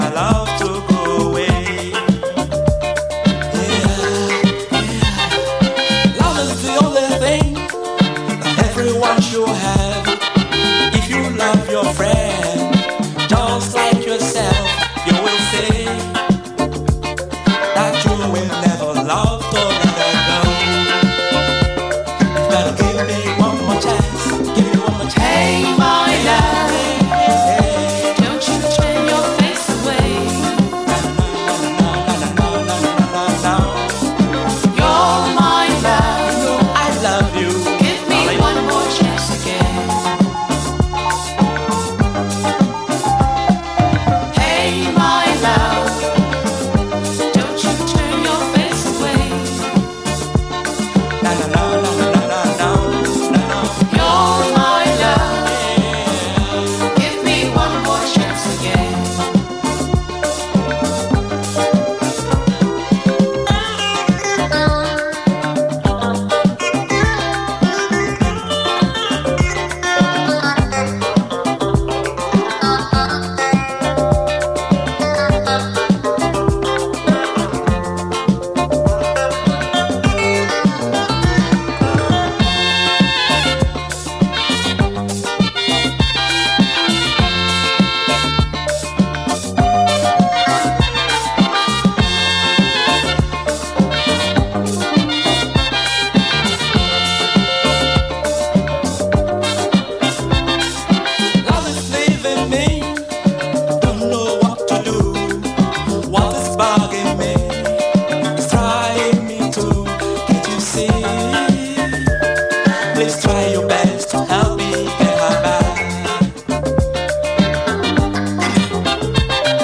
オリジナルは1984年リリースのナイジェリア産激レア・アフロ・ブギー・アルバムが復刻！
レゲエ〜トロピカルなナンバーも収録しており曲のバリエも文句なし！